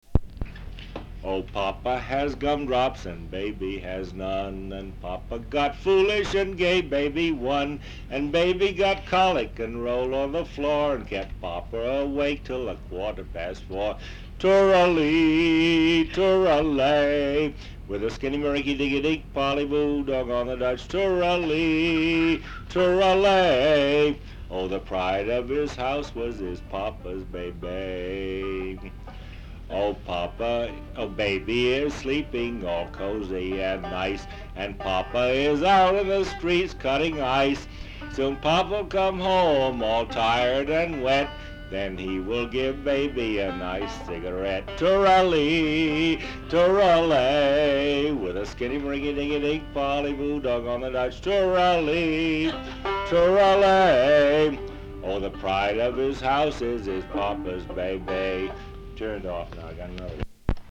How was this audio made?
sound tape reel (analog) West Brattleboro, Vermont